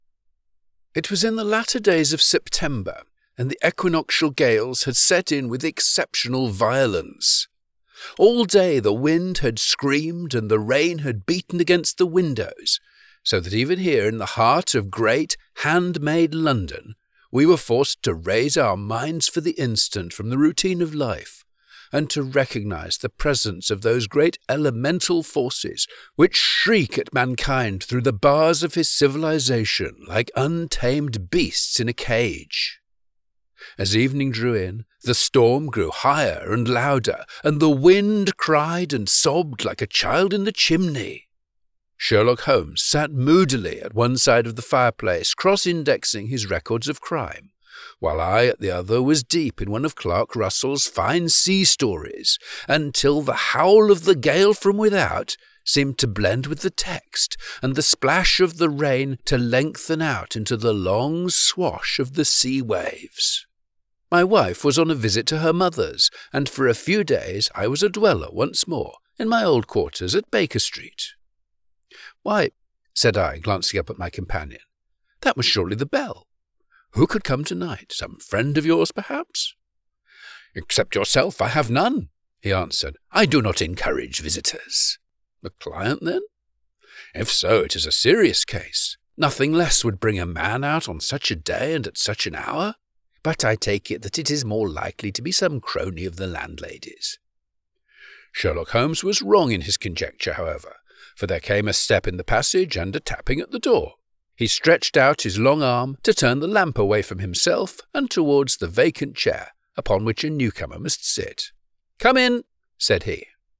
Audiobook Narration
British Audiobook Narrator: